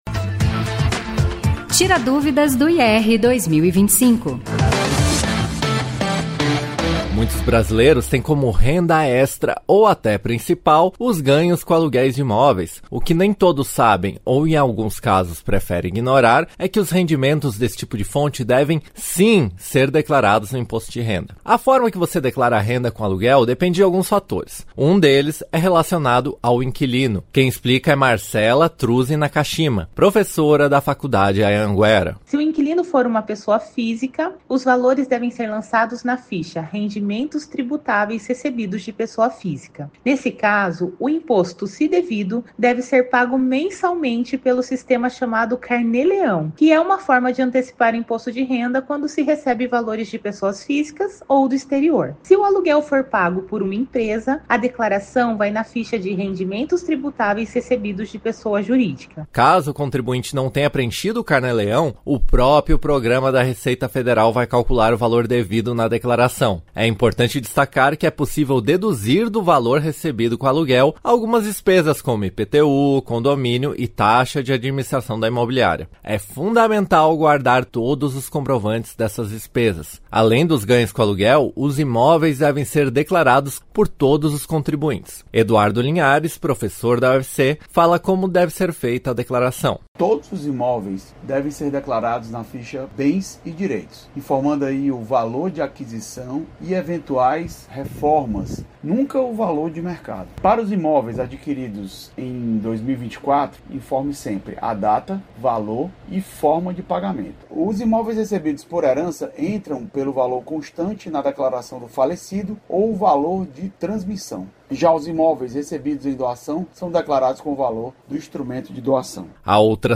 Cem anos em 100 programas 100 anos rádio no Brasil - Arte EBC Até 7 de setembro, a Rádio MEC vai produzir e transmitir, diariamente, interprogramas com entrevistas e pesquisas de acervo sobre diversos aspectos históricos relacionados ao veículo.